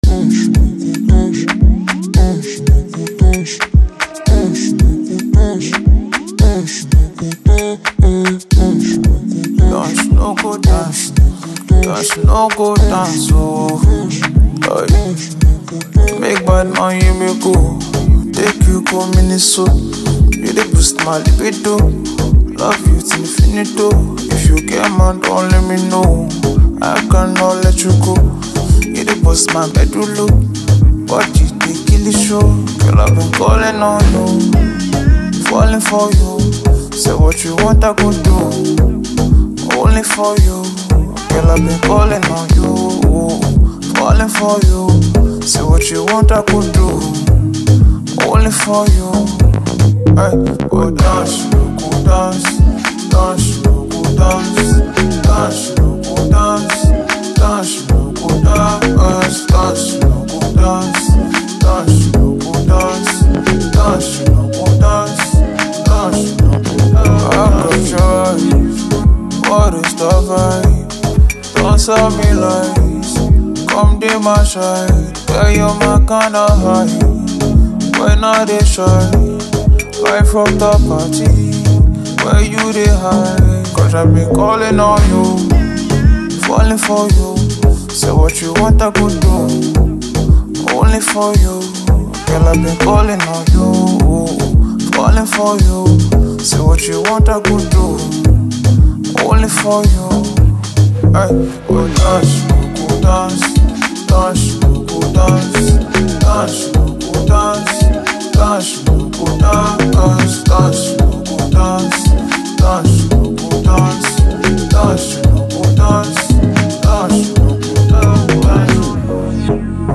Pure feel-good energy.